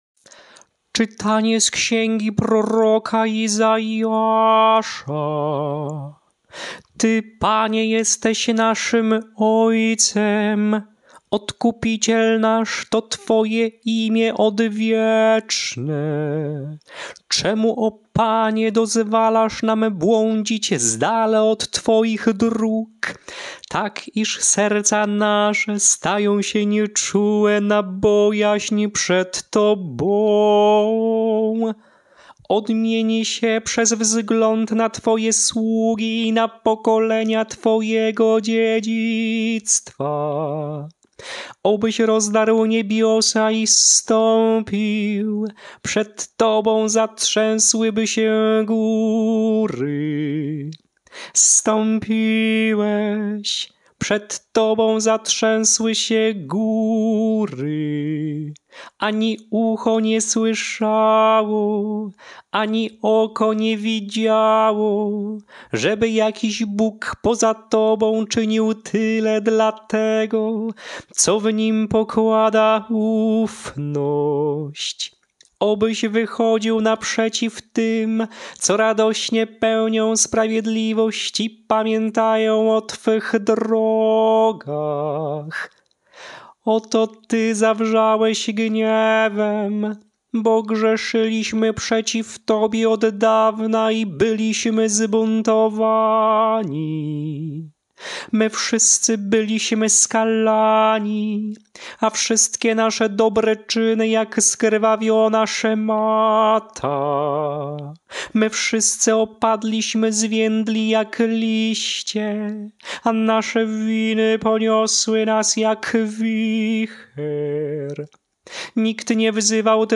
Śpiewane lekcje mszalne – I Niedziela Adwentu
Melodie lekcji mszalnych przed Ewangelią na I Niedzielę Adwentu: